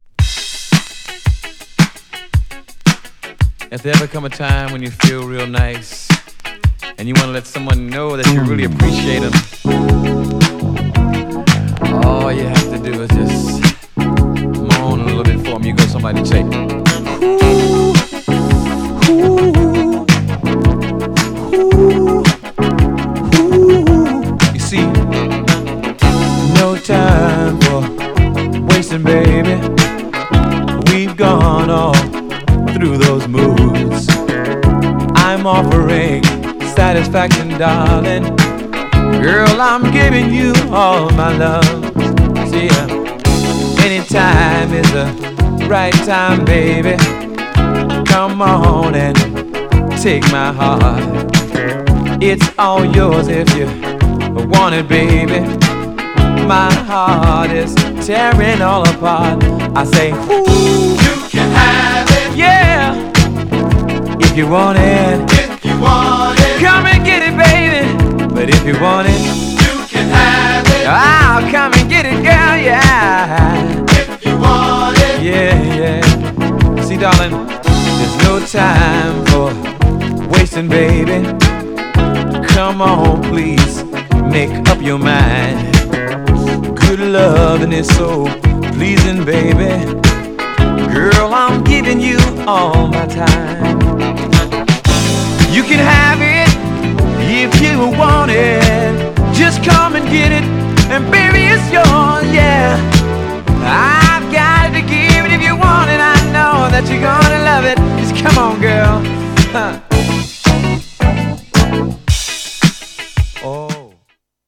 GENRE Dance Classic
BPM 116〜120BPM